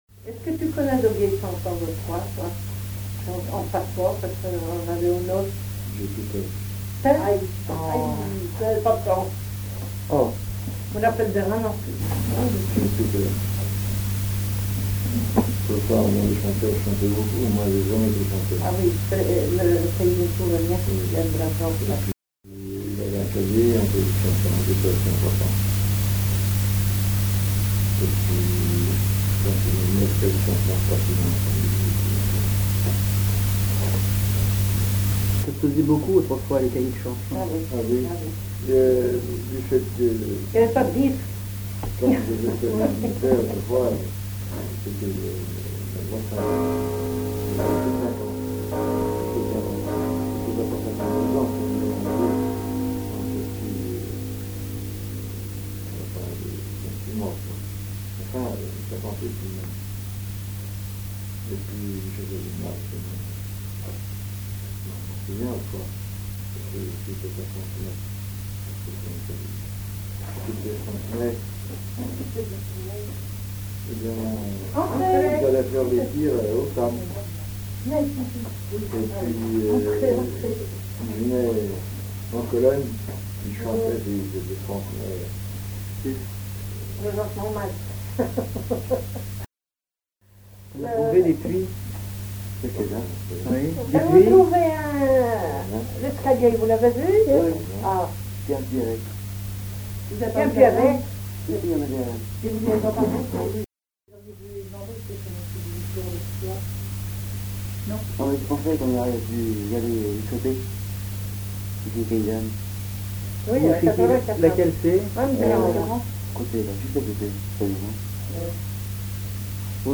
Enquête Les Bottarouzous, de Triaize (Association culturelle)
Catégorie Témoignage